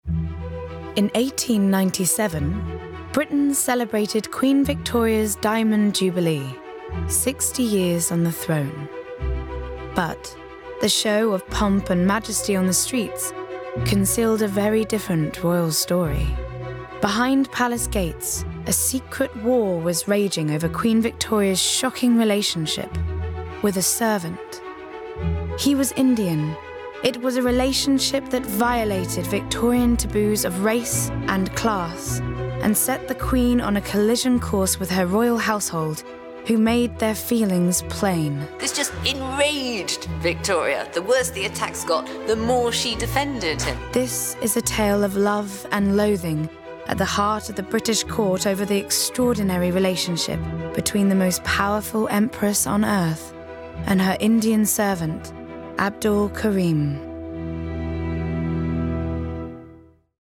20/30's London/Neutral,
Contemporary/Natural/Earthy
• Documentary